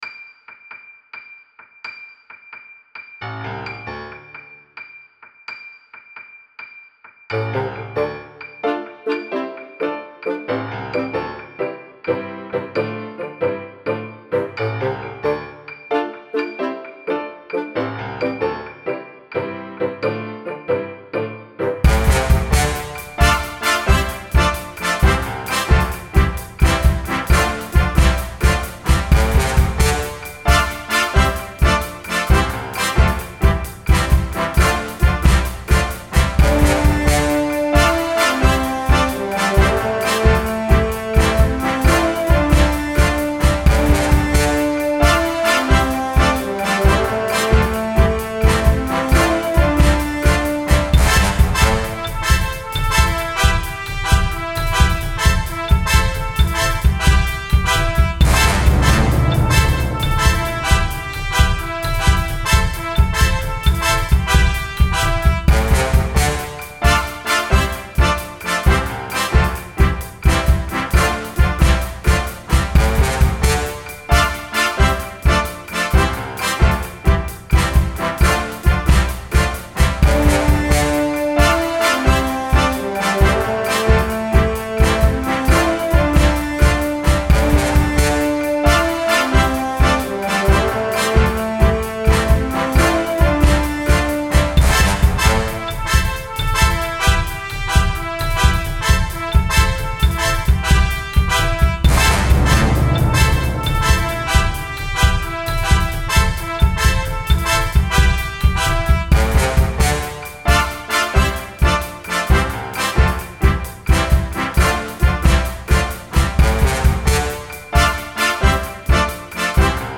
classical